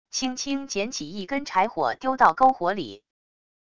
轻轻捡起一根柴火丢到篝火里wav音频